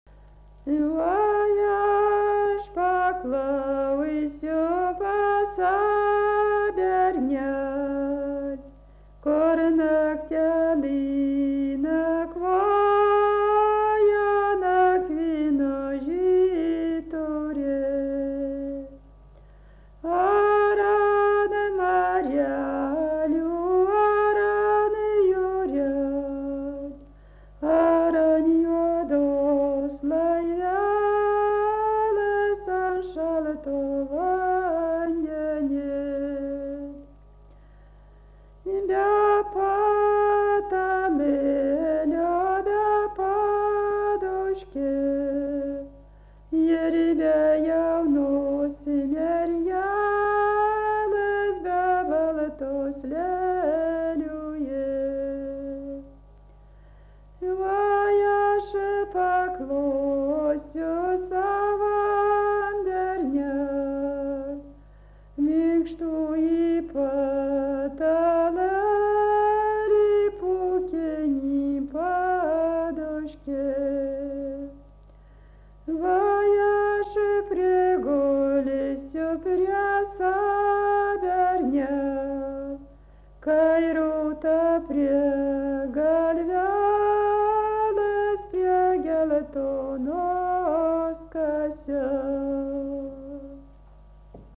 Dalykas, tema daina
Erdvinė aprėptis Dargužiai
Atlikimo pubūdis vokalinis